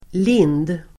Ladda ner uttalet
lind substantiv, lime, (linden [US])Uttal: [lin:d] Böjningar: linden, lindarDefinition: ett lövträd av släktet Tilia